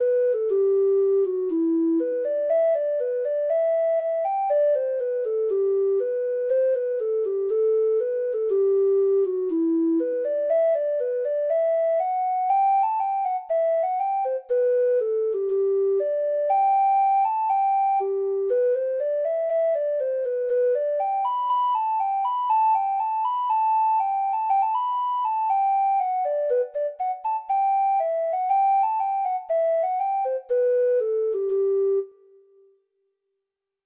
Traditional Music of unknown author.
Traditional Sheet Music